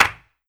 Pluto Clap.wav